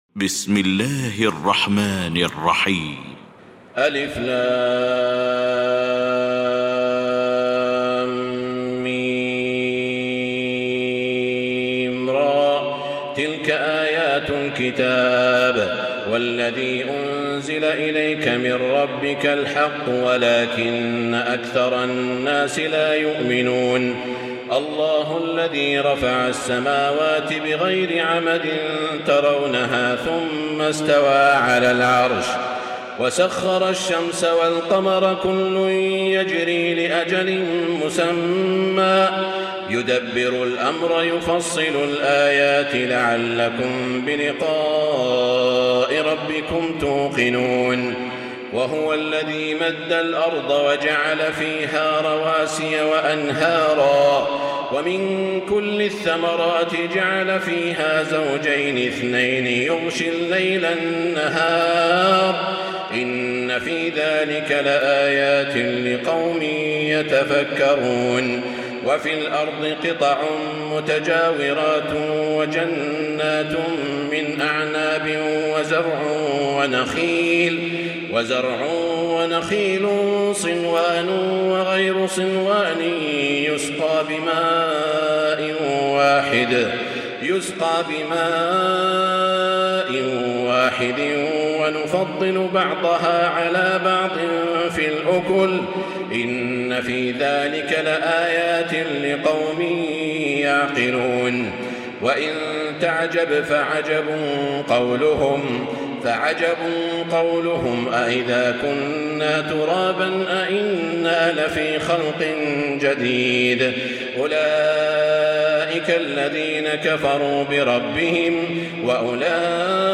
المكان: المسجد الحرام الشيخ: سعود الشريم سعود الشريم فضيلة الشيخ ياسر الدوسري الرعد